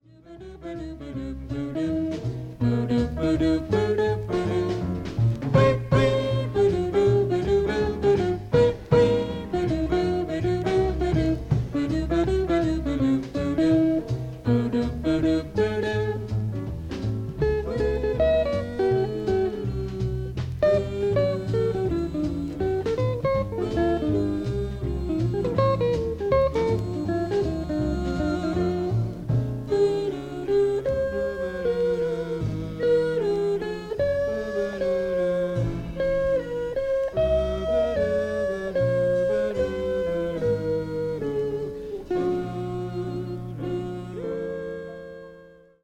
軽やかなスキャット。テクニカルなヴォーカリーズ。
ジャズ・コンボをバックに抜群の歌唱力で、ときに神妙に、ときに溌溂と。